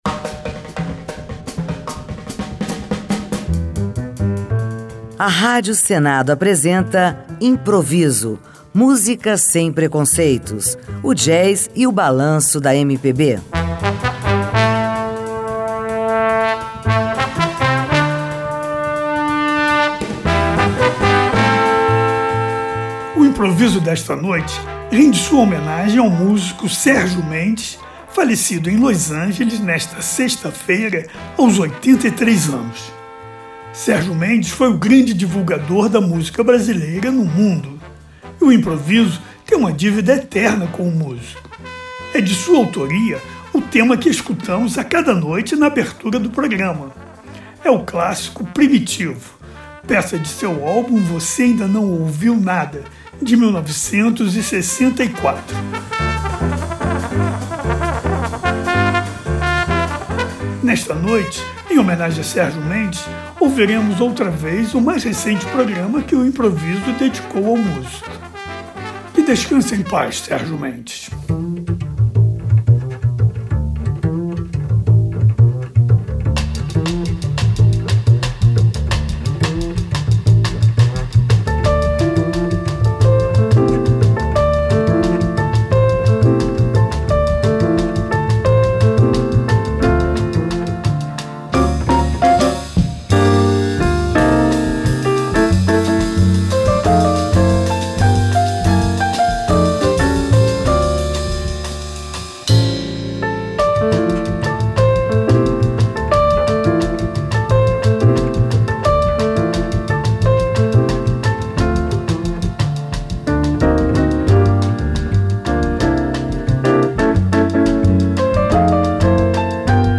samba-jazz